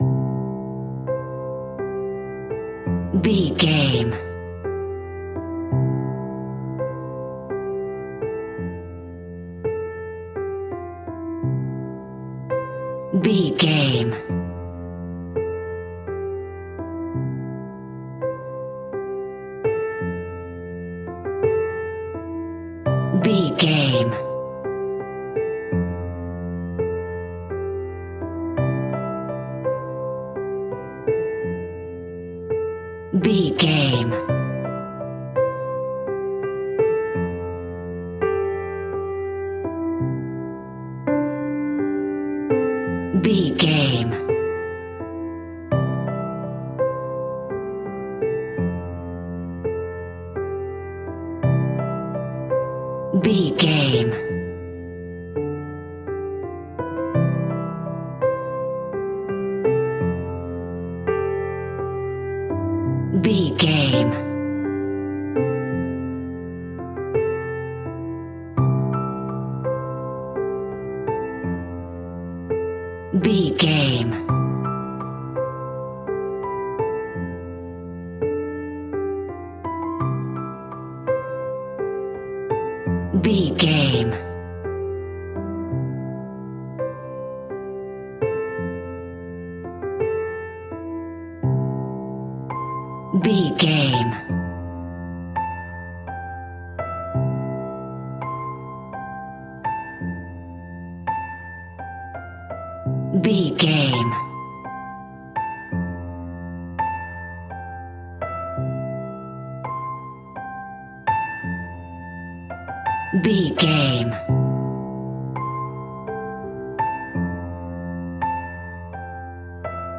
Soothing bright and happy piano music in a major key.
Regal and romantic, a classy piece of classical music.
Ionian/Major
romantic
soft